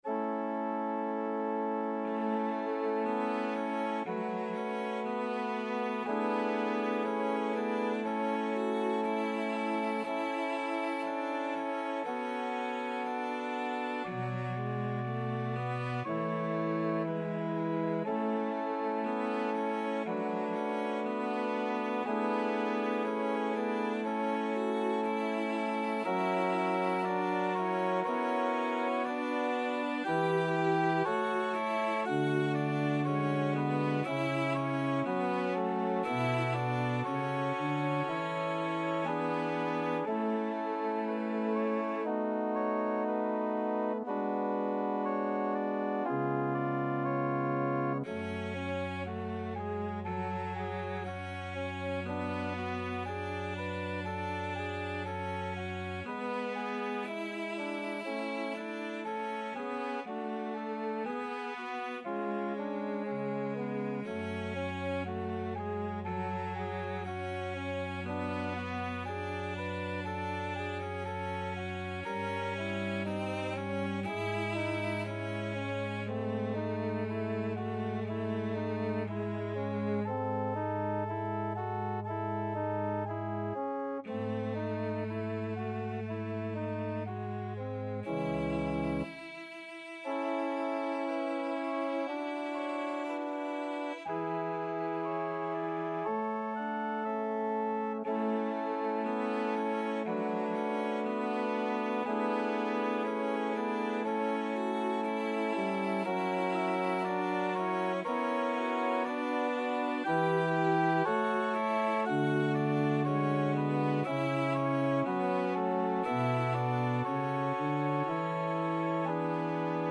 Classical Saint-Saëns, Camille Ave Maria in A major Cello version
Cello
2/4 (View more 2/4 Music)
A major (Sounding Pitch) (View more A major Music for Cello )
~ = 100 Andantino sempre legato =60 (View more music marked Andantino)
Classical (View more Classical Cello Music)